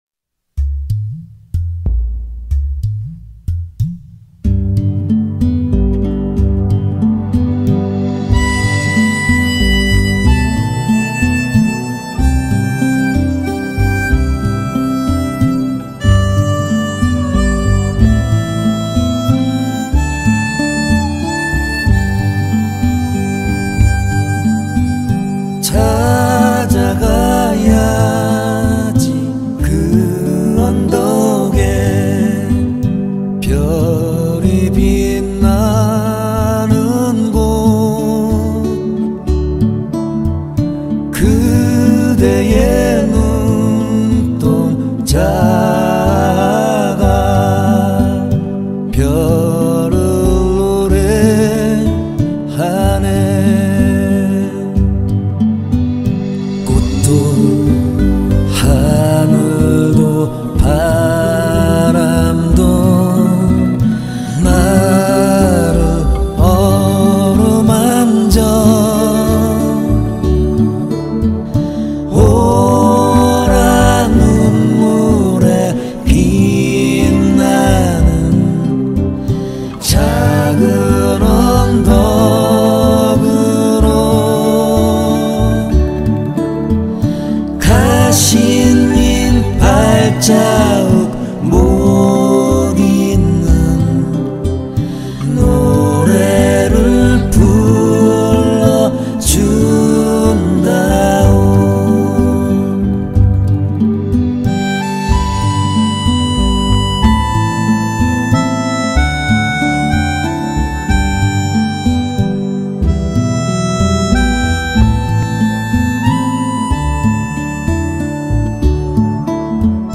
가요 모음
ALBUM TYPE: 정규, studio, 5집.